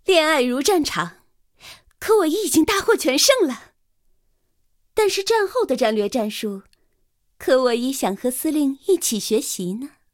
KV-1誓约语音.OGG